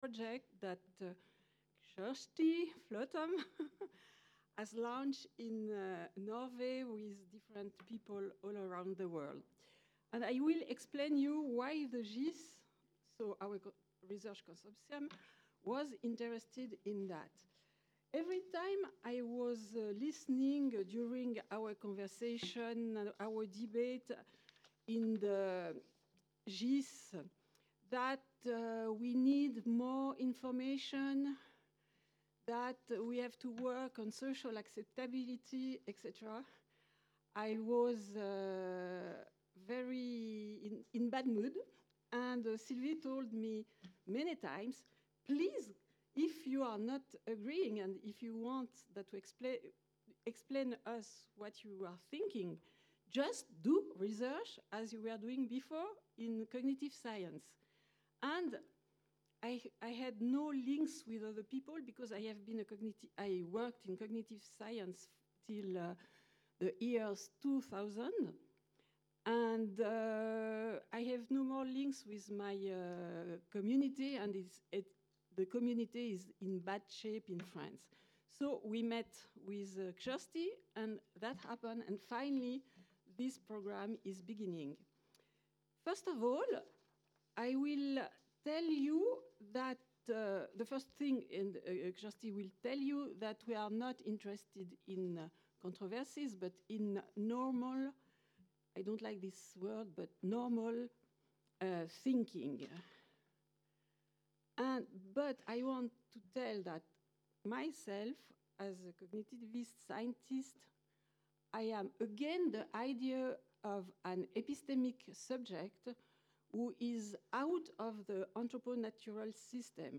The workshop "Individual and collective representations of climate change : interdisciplinary perspectives", organized by the Paris Consortium Climate-Environment-Society (GIS Climat- Environnement-Société), was held in Meudon on March 8, 2013.